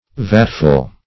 vatful - definition of vatful - synonyms, pronunciation, spelling from Free Dictionary Search Result for " vatful" : The Collaborative International Dictionary of English v.0.48: Vatful \Vat"ful\, n.; pl.
vatful.mp3